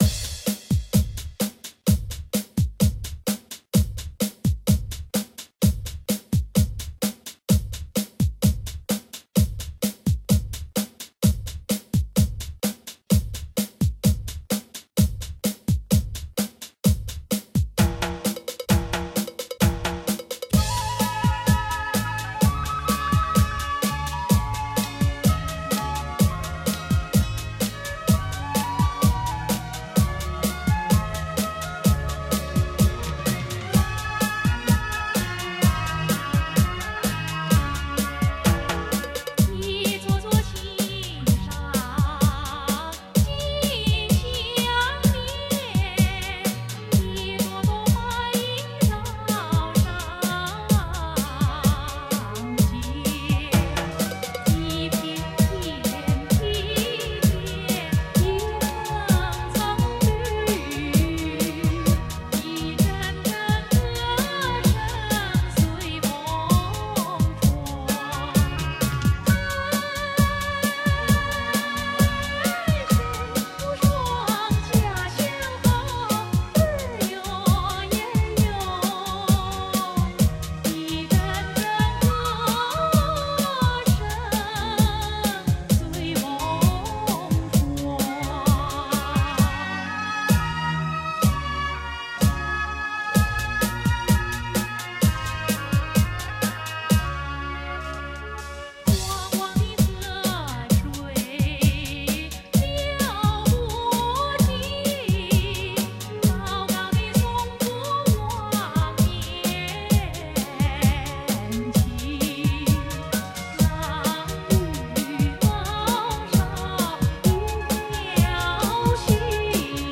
新潮 cha cha